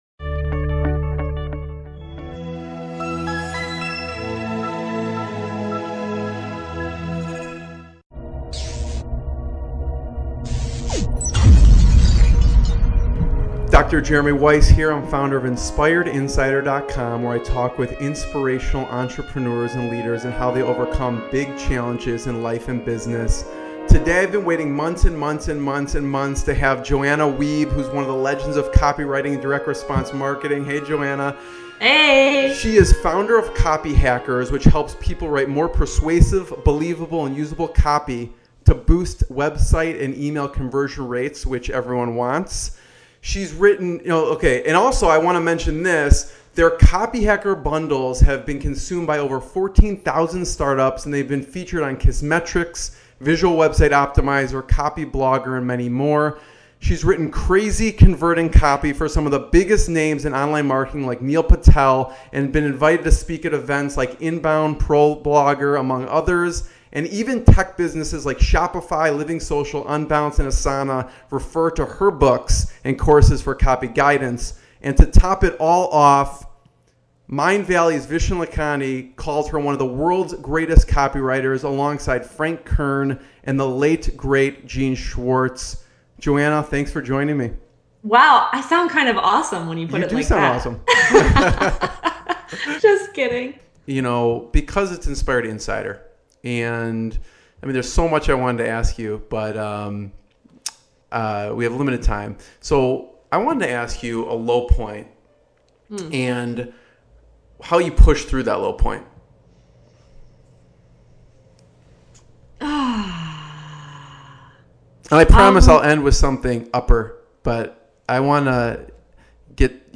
What will you learn in this interview?